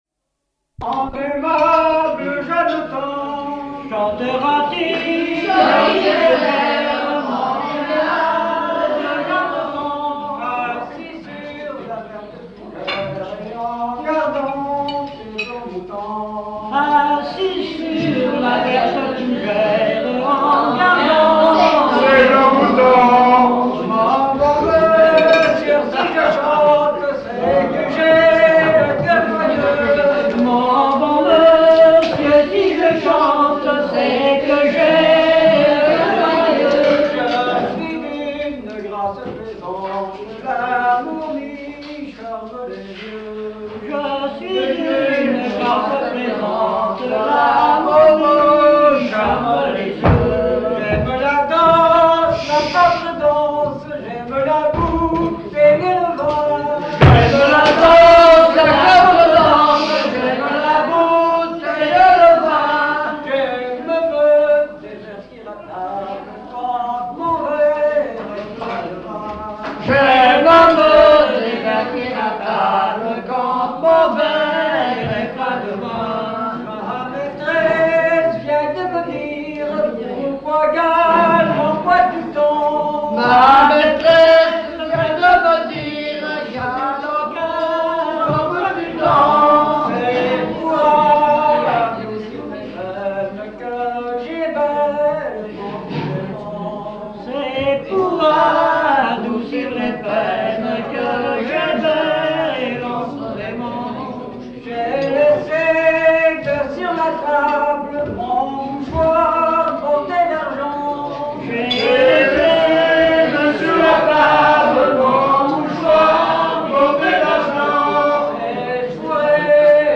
Genre strophique
répertoire lors du repas de noce
Pièce musicale inédite